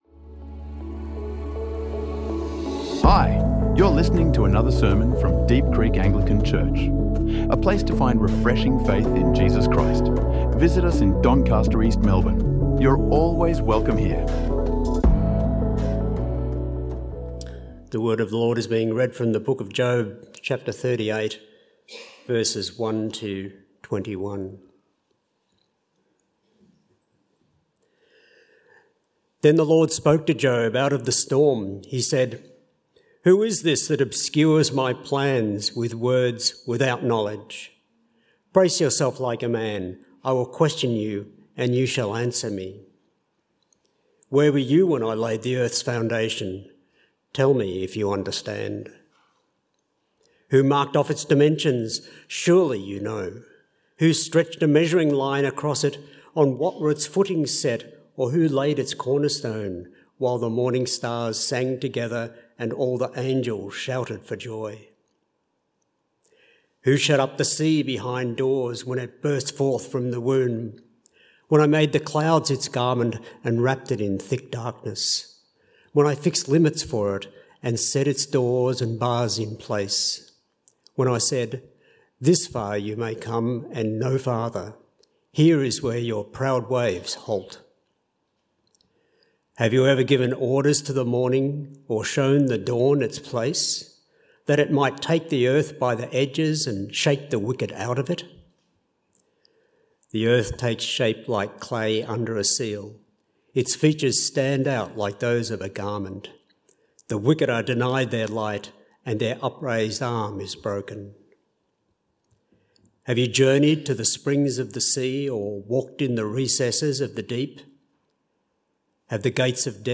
In this powerful sermon, we explore how God responds from the storm, lifting Job’s eyes from suffering to the wonder, wisdom, and wildness of creation.